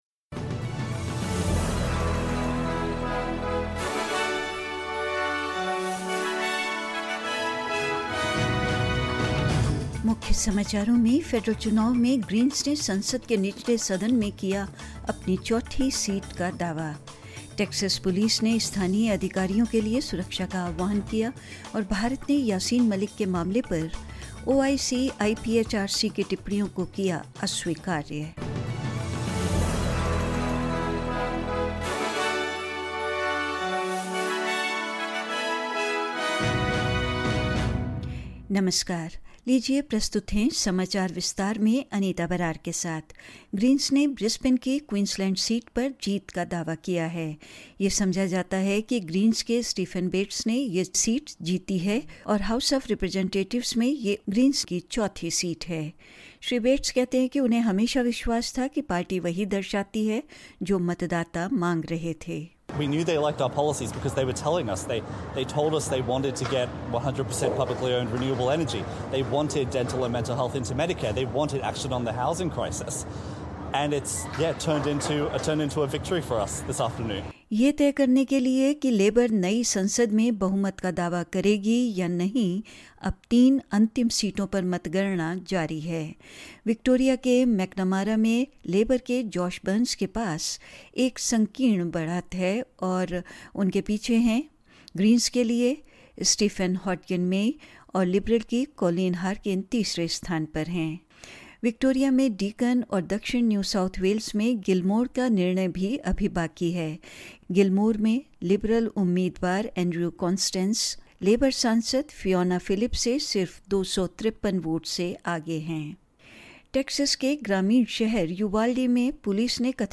In this latest SBS Hindi bulletin: The Greens claim their fourth seat in the lower house of Parliament following the federal election; Texas police call for protection for local officers amid revelations they waited outside the classroom of a deadly school shooting; The impact of the COVID-19 pandemic still being felt by school students and more news.